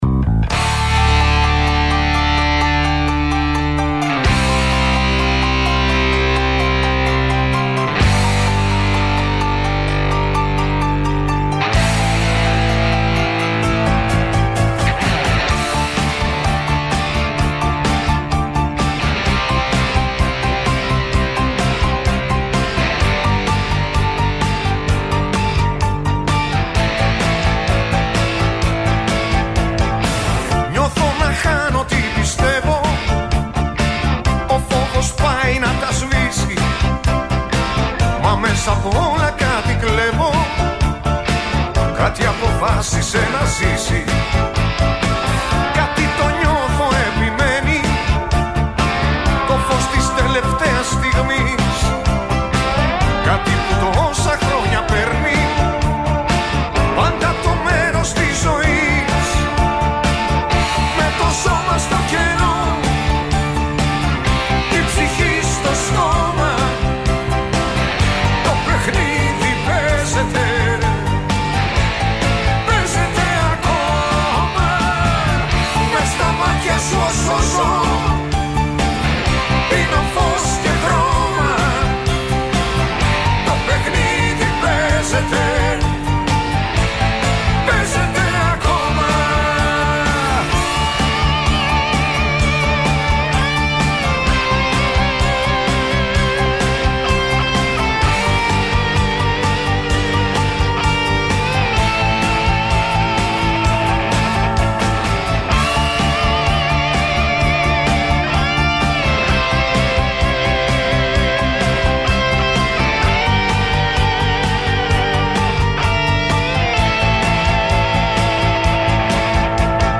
Excerpt from 04/09/2014 "Londino Kalimera" radio show on London Greek Radio 103,3 FM .